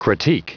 Prononciation du mot critique en anglais (fichier audio)
Prononciation du mot : critique